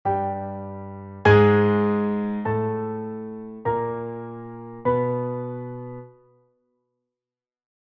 der Ton As, Notation
der-Ton-As.mp3